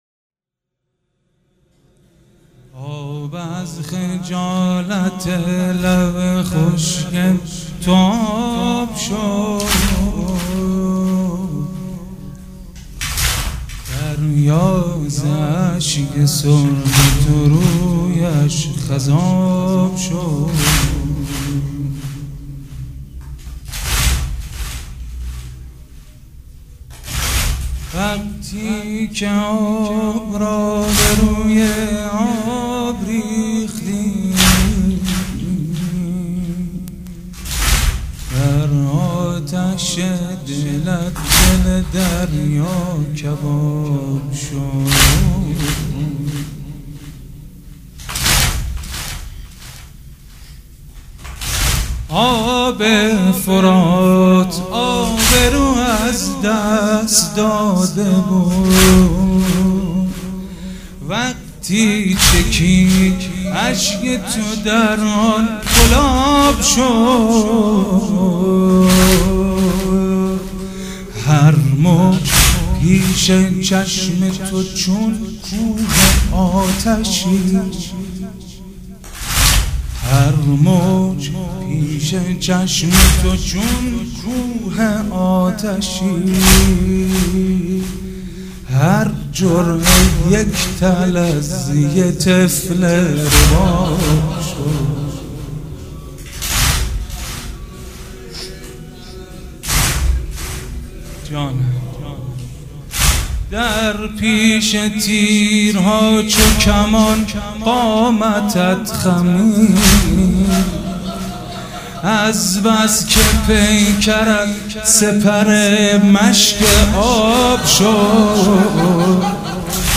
وفات حضرت ام البنین(س) 1396